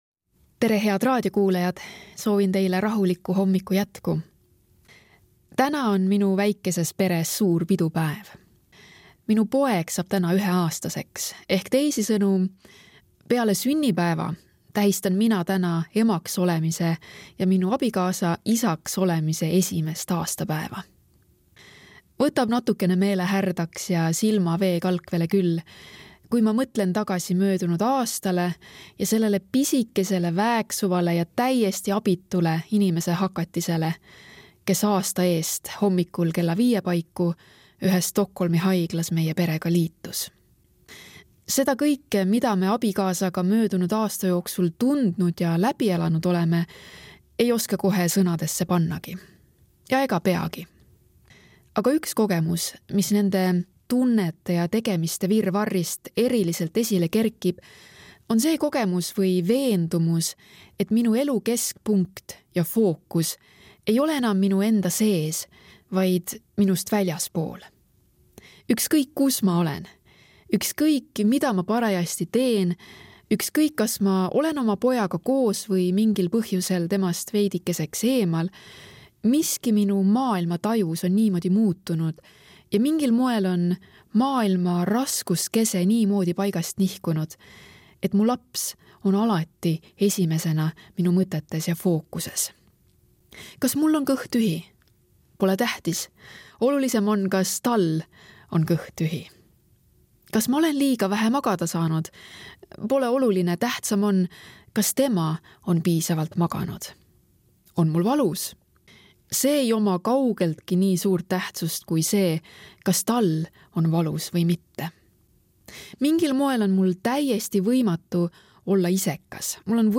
hommikumõtisklus ERR-s 10.11.2025